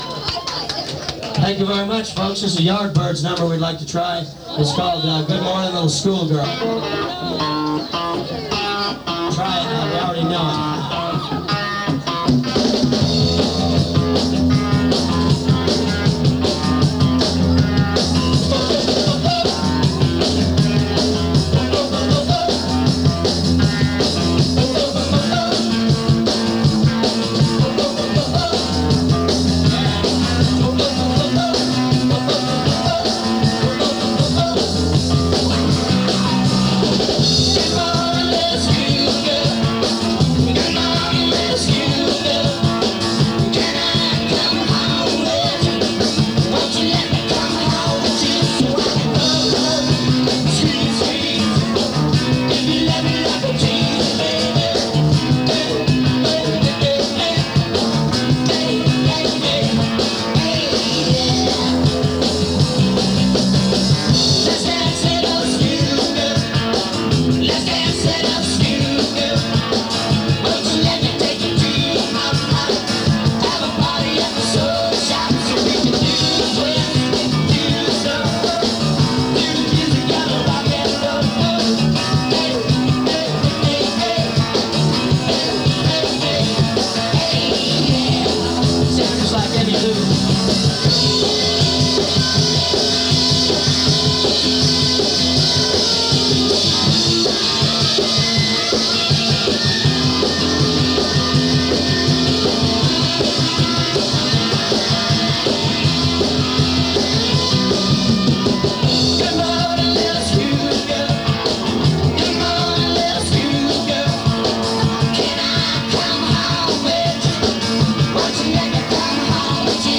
Live In 1988